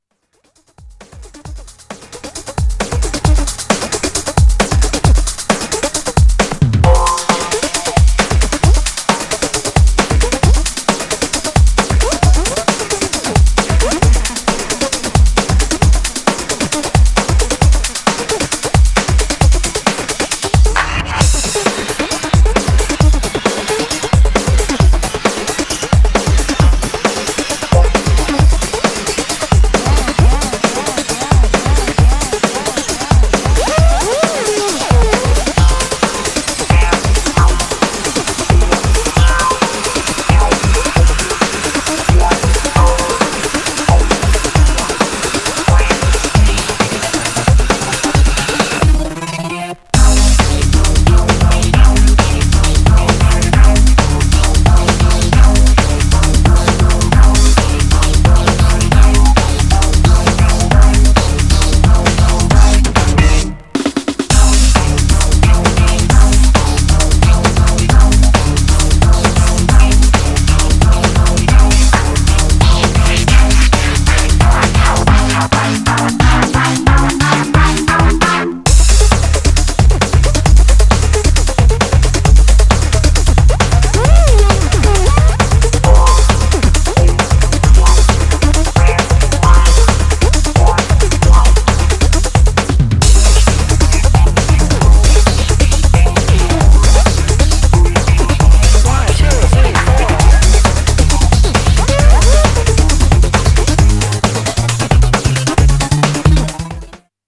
Styl: Breaks/Breakbeat